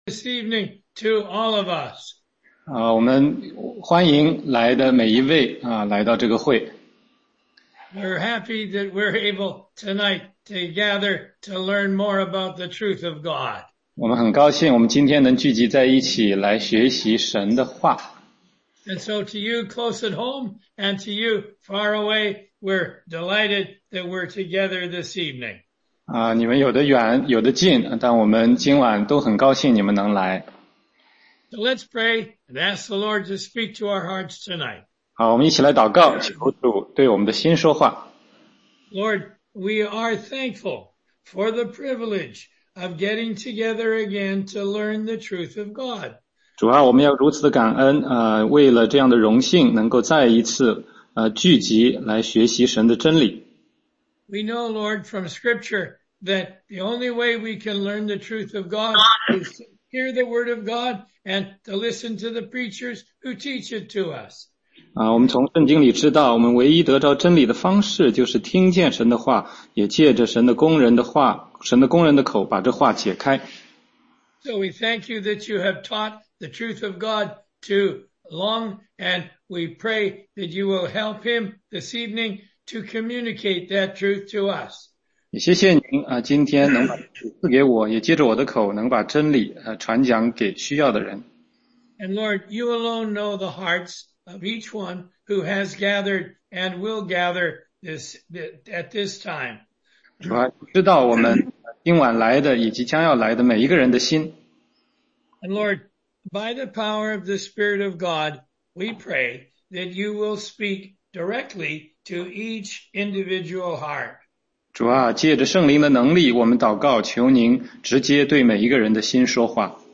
16街讲道录音 - 雅各的一生对基督徒的启示之四